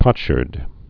(pŏtshûrd) also pot·shard (-shärd)